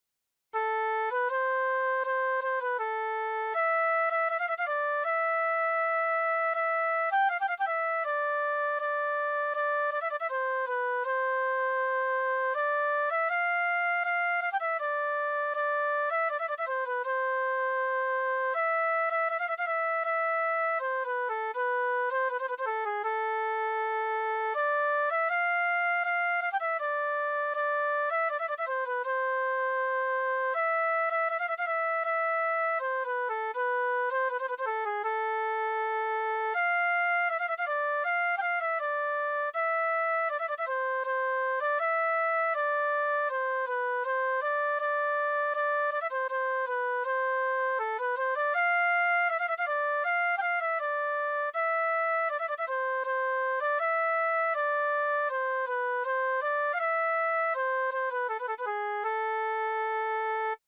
Instrument: Accordéon Գործիք՝ Ակորդեոն